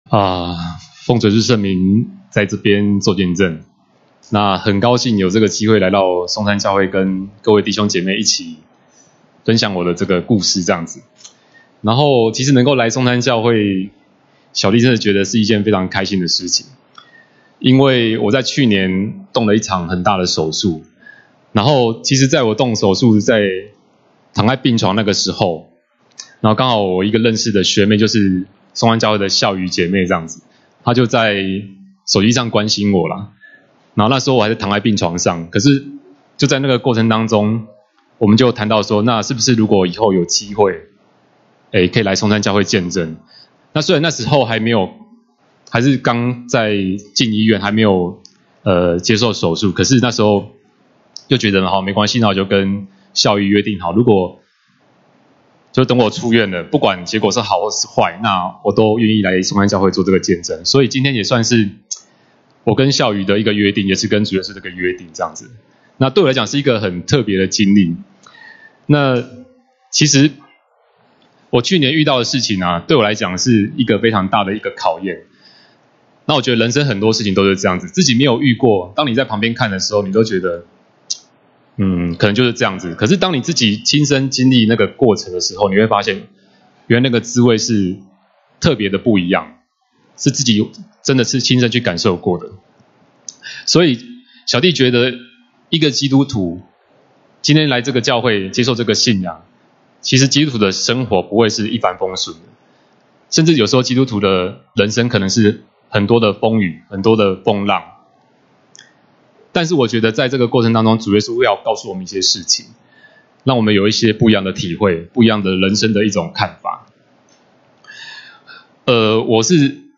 2018年1月份講道錄音已全部上線